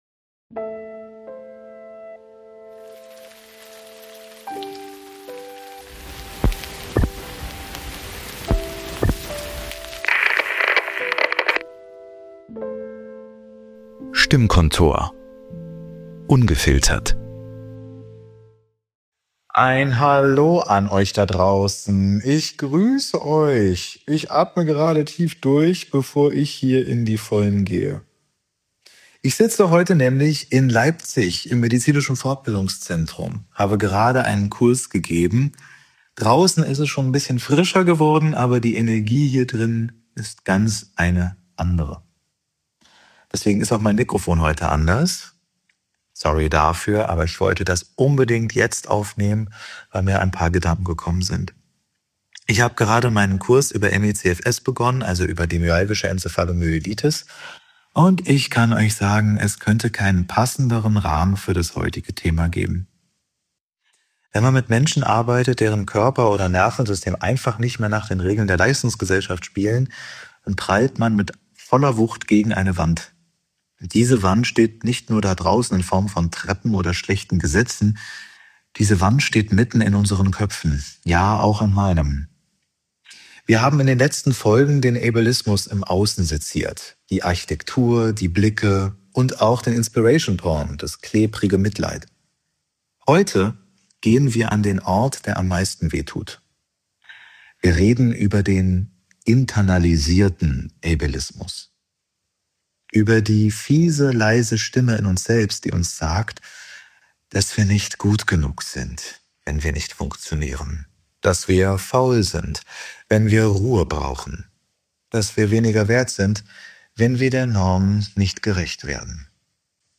Beschreibung vor 3 Tagen Ich sitze heute in Leipzig, mein Kurs über ME/CFS hat gerade begonnen, und ein Thema hängt wie eine bleierne Wolke im Raum: Der internalisierte Ableismus.
Dies ist kein gemütlicher Plausch.